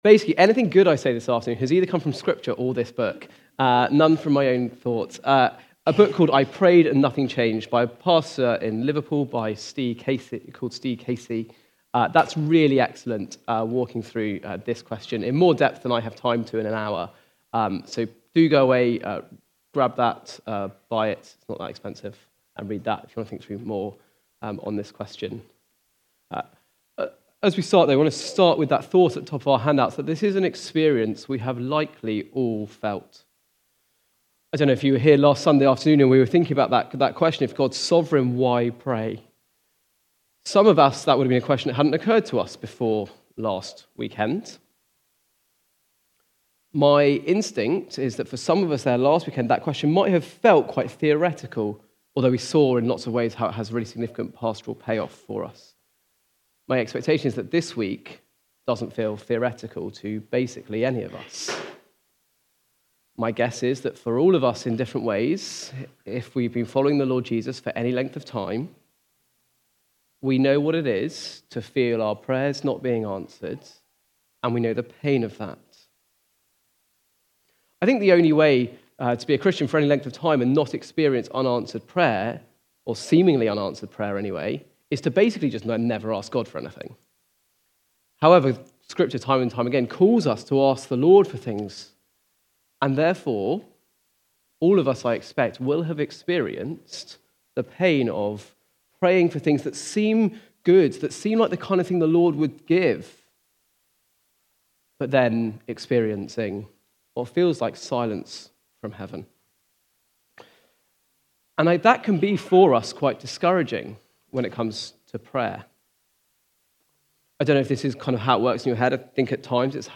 When God Doesn't Answer Prayer... (2 Corinthians 12:1-10) from the series A Vision for 2025. Recorded at Woodstock Road Baptist Church on 12 January 2025.